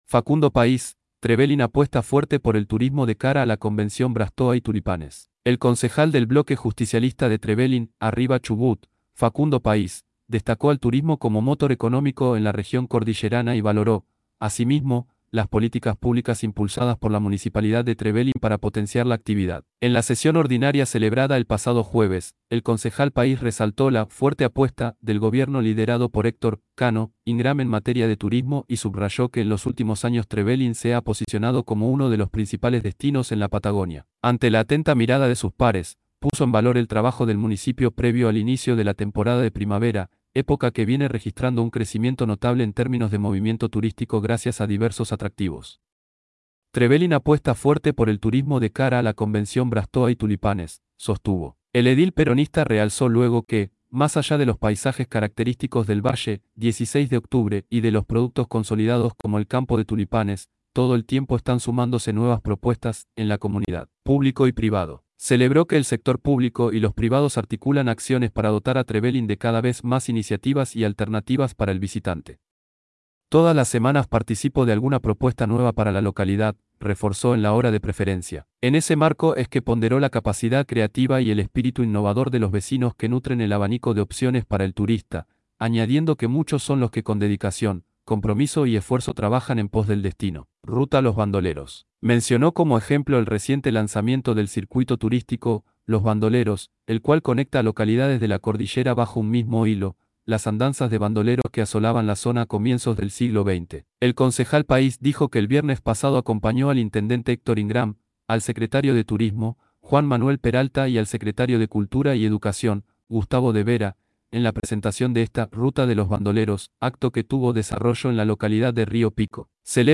El concejal del bloque justicialista de Trevelin “Arriba Chubut”, Facundo Pais, destacó al turismo como motor económico en la región cordillerana y valoró, asimismo, las políticas públicas impulsadas por la Municipalidad de Trevelin para potenciar la actividad. En la sesión ordinaria celebrada el pasado jueves, el concejal Pais resaltó la “fuerte apuesta” del gobierno liderado por Héctor “Cano” Ingram en materia de turismo y subrayó que en los últimos años Trevelin se ha posicionado como uno de los principales destinos en la Patagonia.
concejal_facundo_pais_-_xi_sesion_hcd_2024.mp3